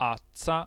[ts]